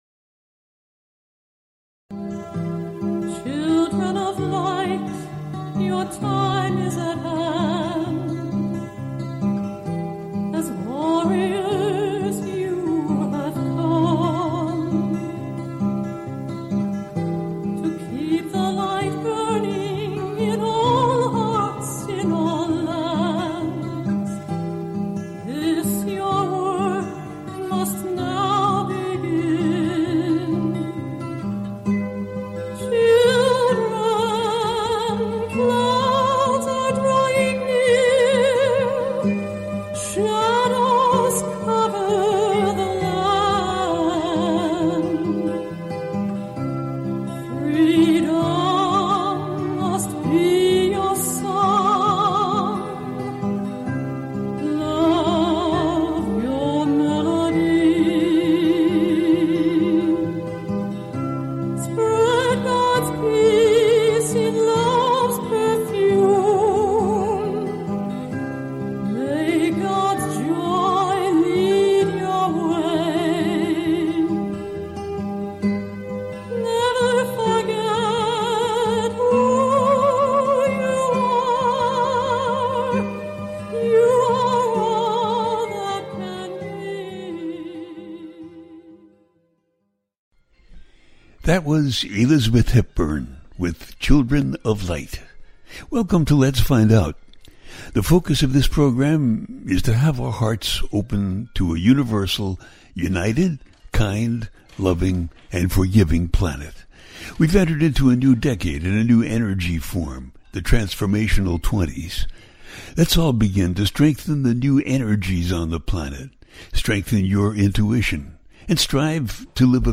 It's Your Night tonight-Call Ins, plus this weeks energies-Solar Eclipse on Friday
The listener can call in to ask a question on the air.
Each show ends with a guided meditation.